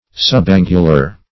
Subangular \Sub*an"gu*lar\, a. Slightly angular.